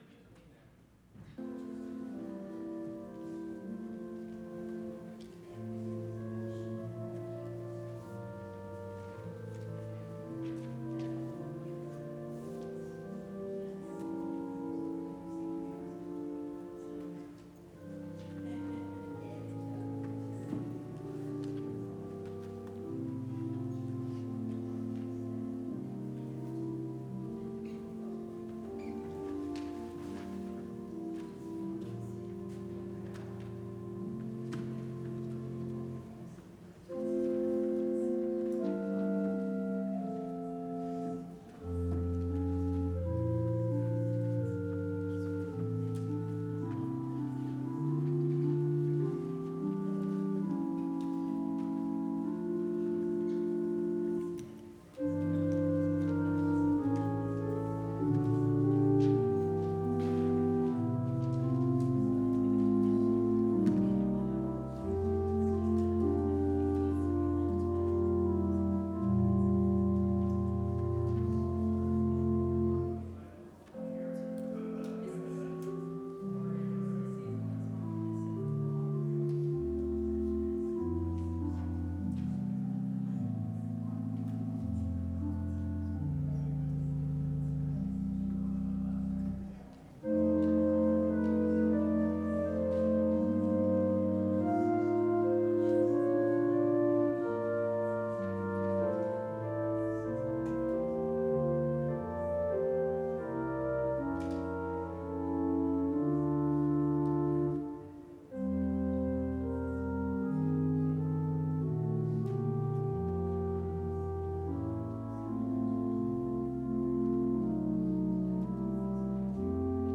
Worship Service Sunday December 21, 2025